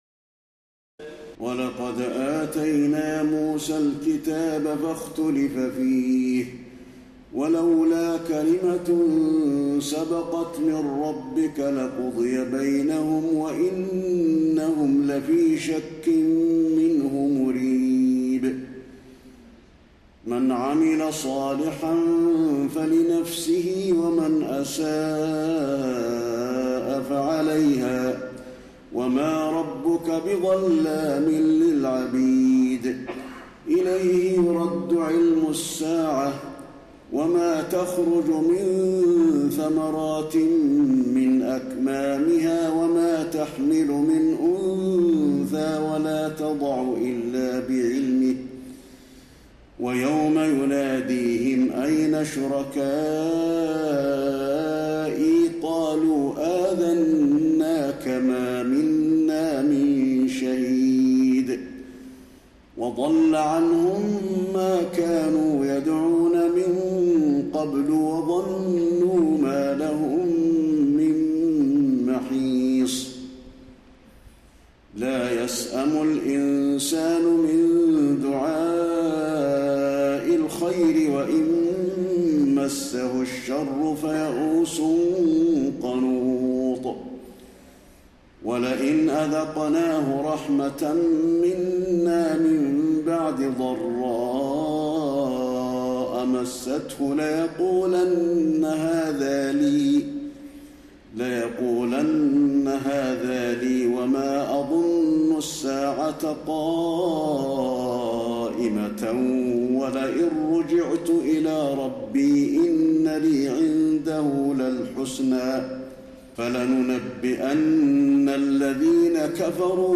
تراويح ليلة 24 رمضان 1432هـ من سور فصلت (45-54) و الشورى و الزخرف (1-25) Taraweeh 24 st night Ramadan 1432H from Surah Fussilat and Ash-Shura and Az-Zukhruf > تراويح الحرم النبوي عام 1432 🕌 > التراويح - تلاوات الحرمين